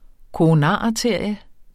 Udtale [ koʁoˈnɑˀ- ]